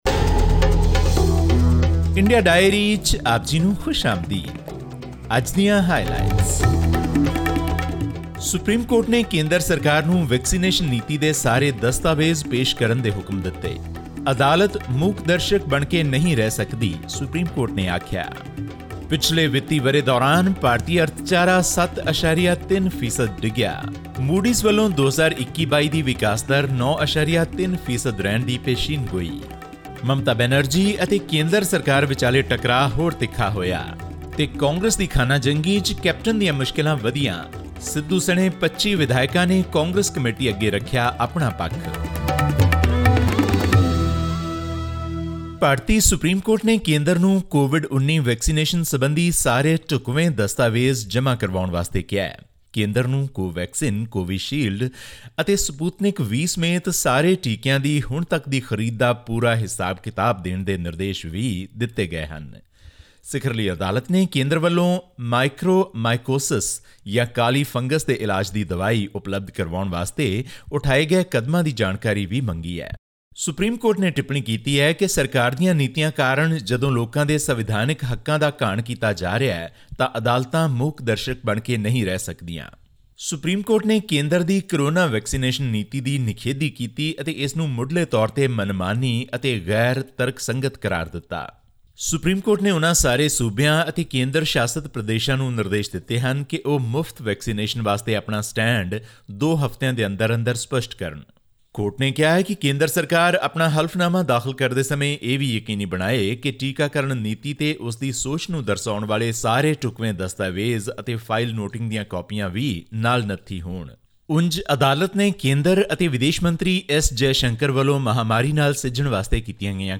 All this and more in our weekly news segment from India.